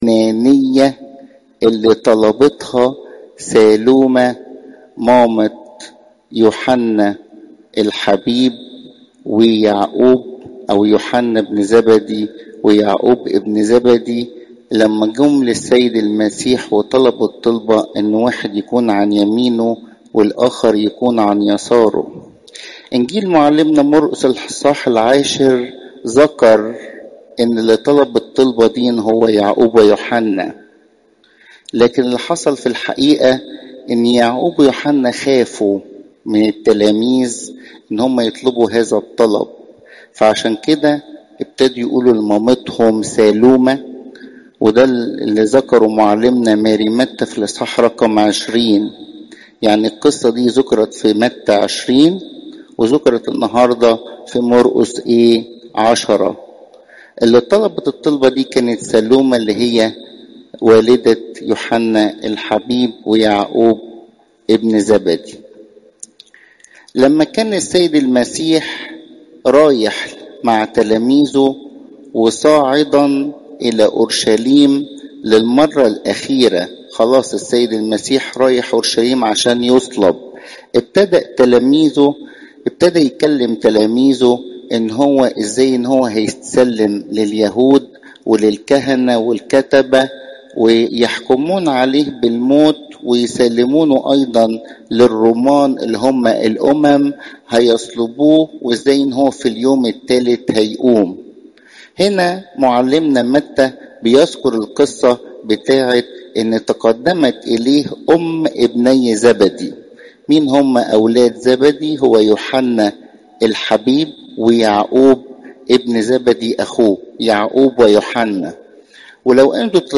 عظات قداسات الكنيسة (مر 10 : 35 - 45)